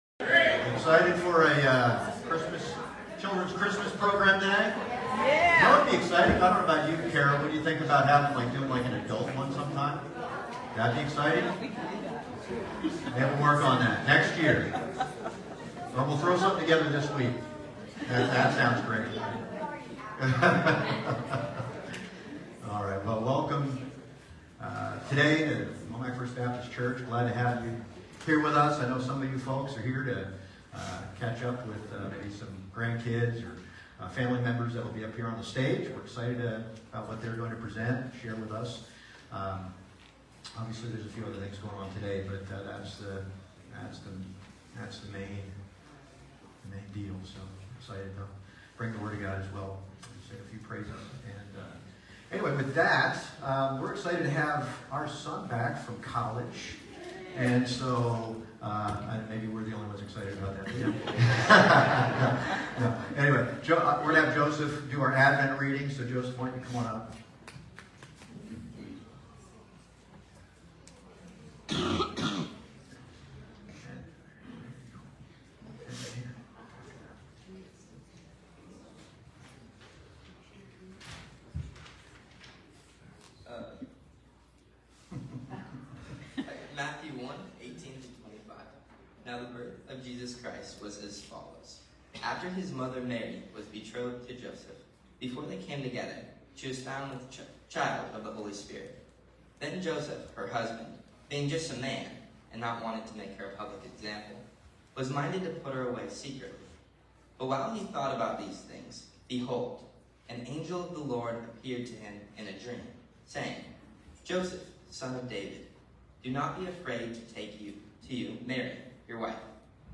The Children’s Christmas Program